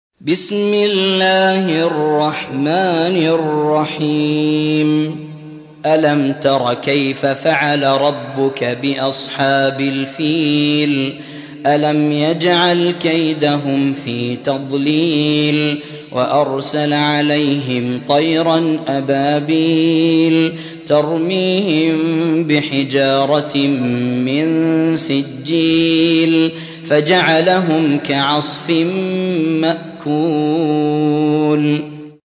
105. سورة الفيل / القارئ